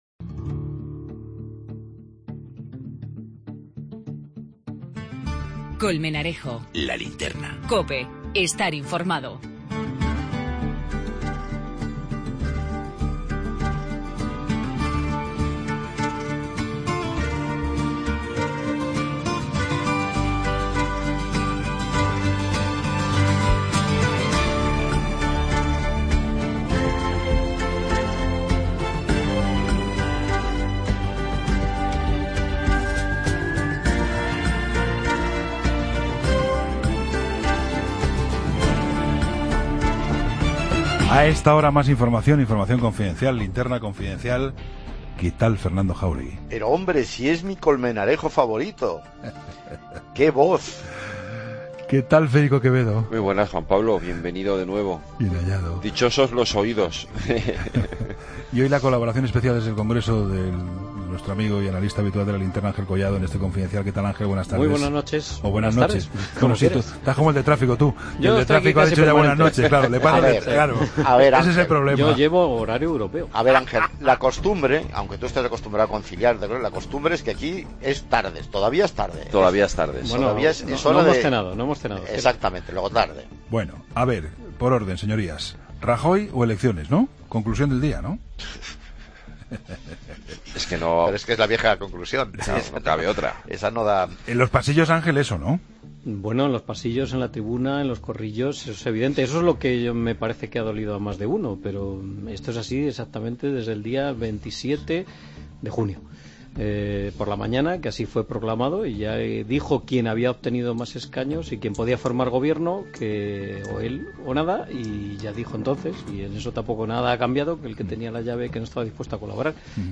desde el Congreso.